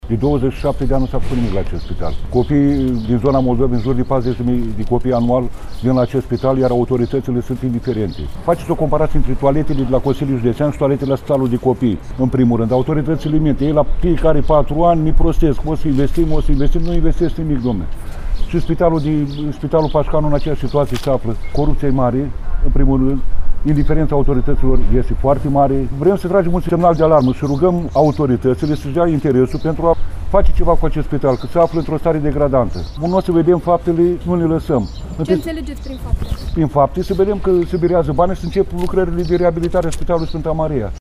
14-iun-rdj-17-vox-protest.mp3